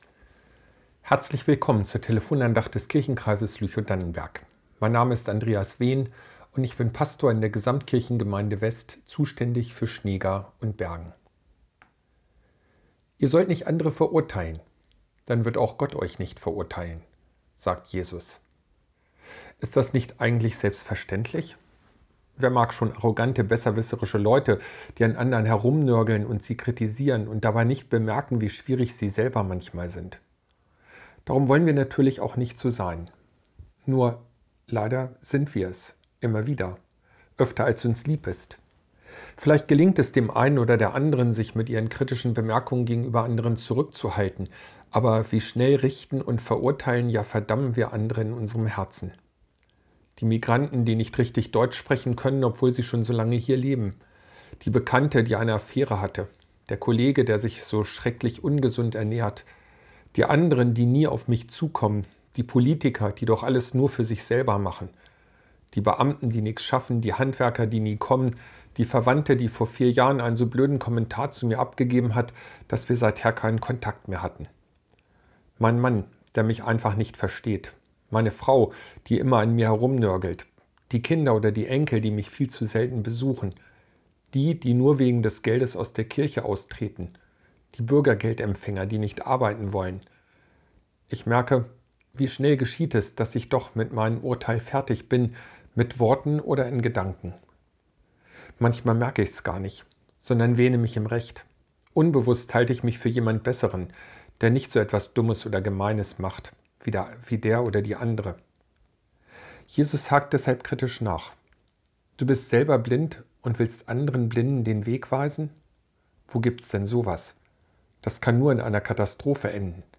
Ertappt ~ Telefon-Andachten des ev.-luth. Kirchenkreises Lüchow-Dannenberg Podcast